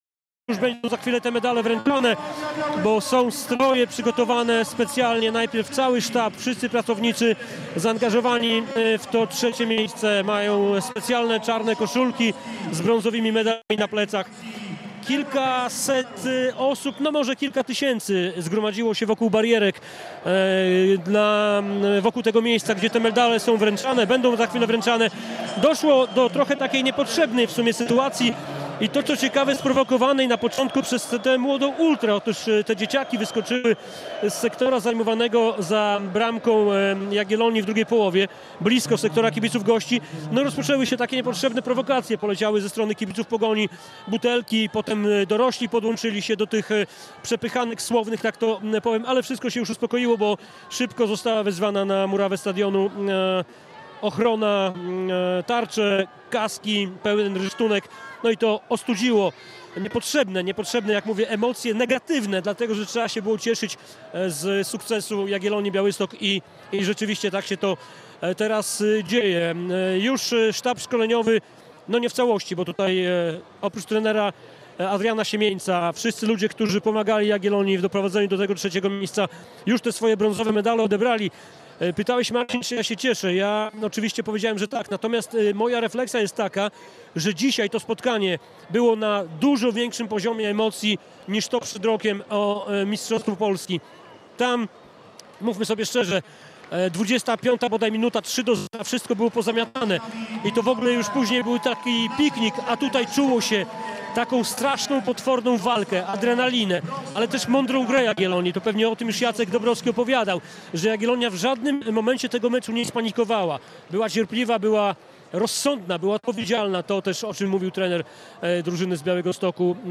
Ceremonia medalowa - relacjonuje